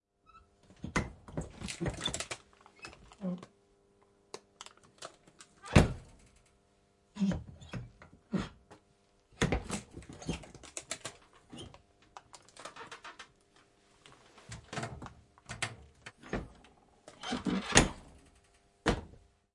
废品收购站 " 旧冰箱
描述：使用Zoom H6 XY Mic录制。
打开并关闭旧的苏联冰箱。
Tag: 厨房 打开 关闭 冰箱